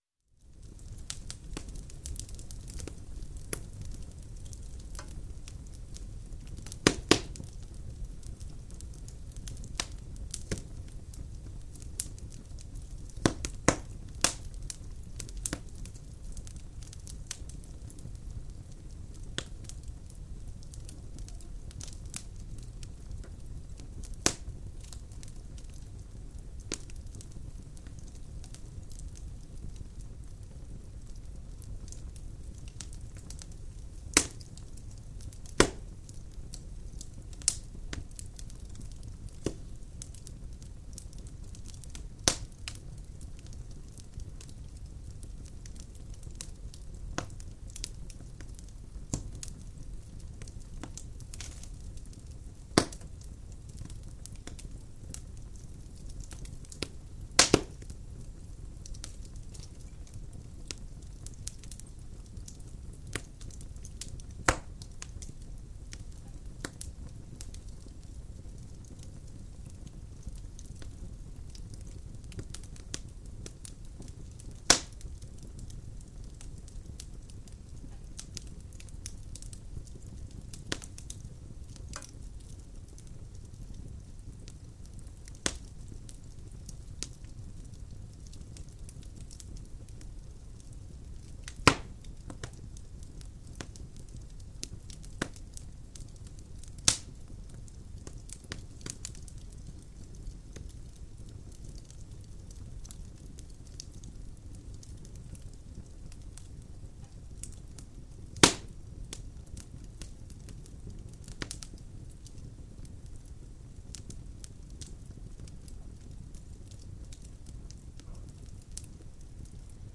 В подборке представлены природные звуки, медитативные мелодии и фоновые шумы для релаксации, работы и сна.
Дрова ярко разгорелись в печке бани